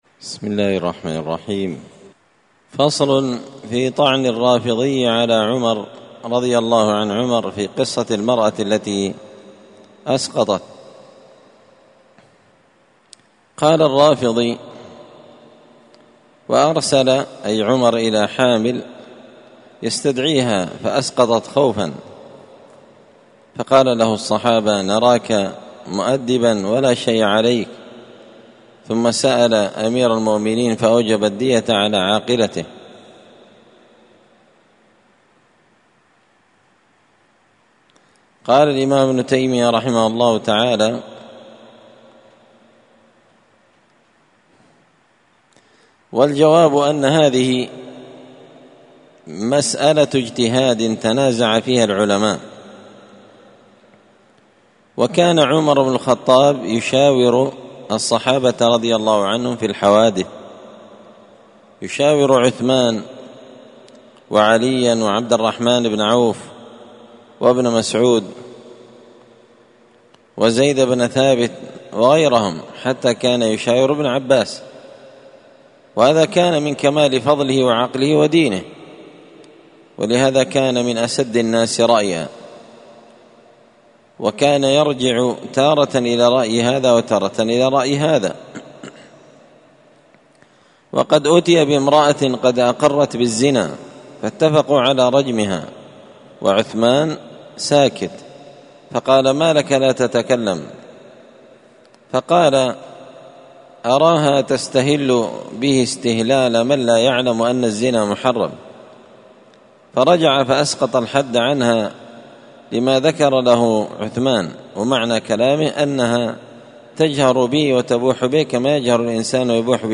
الخميس 18 ذو الحجة 1444 هــــ | الدروس، دروس الردود، مختصر منهاج السنة النبوية لشيخ الإسلام ابن تيمية | شارك بتعليقك | 6 المشاهدات